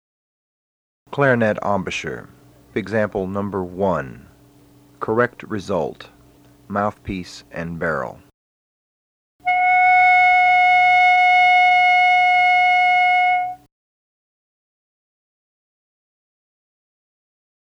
THE FIRST TONE
When it happens , the sound of mouthpiece and barrel produces concert F# (clarinet G#) and should sound like this: